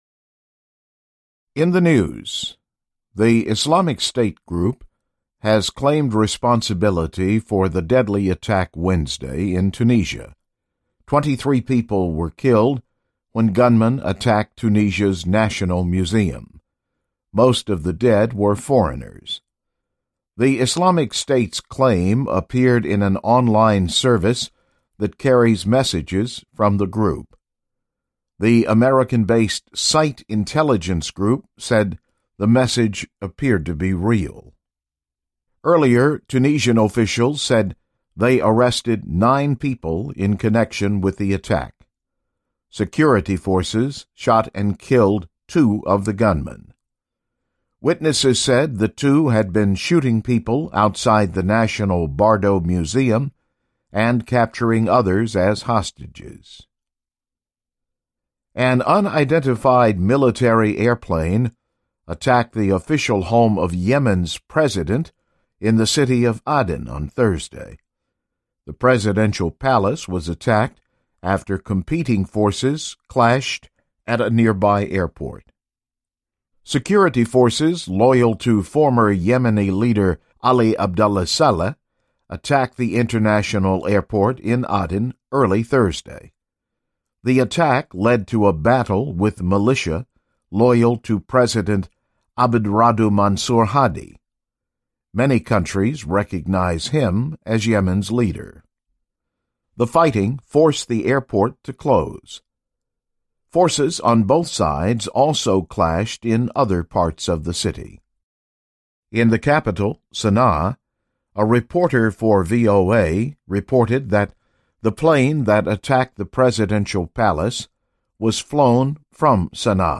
This newscast was based on stories from VOA’s News Division.